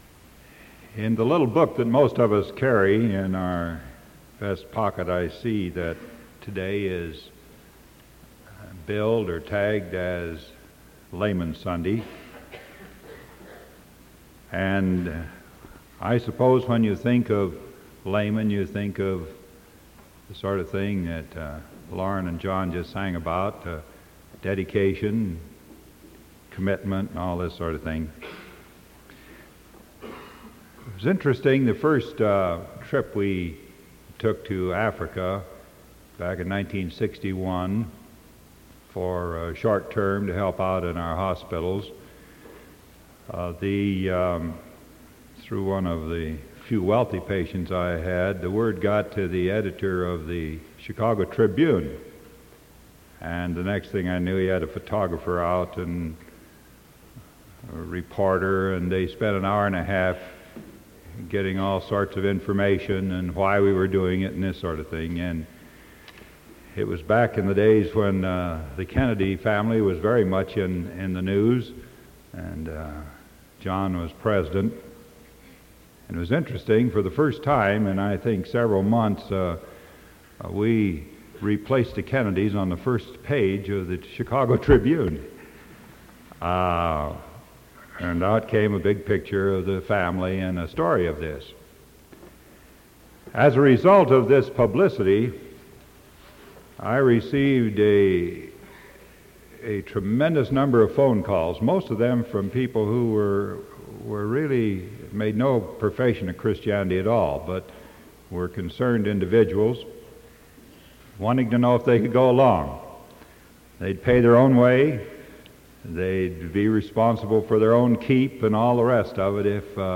Sermon October 12th 1975 PM